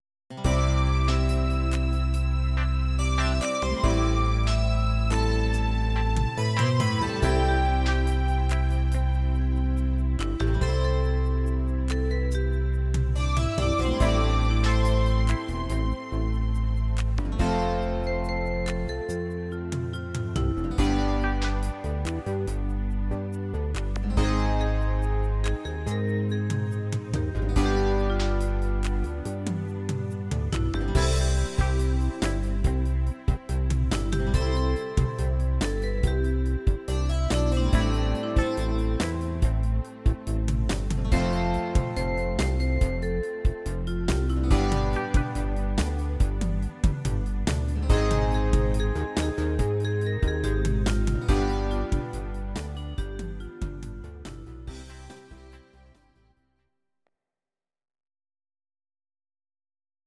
Audio Recordings based on Midi-files
Our Suggestions, Pop, Ital/French/Span, 1990s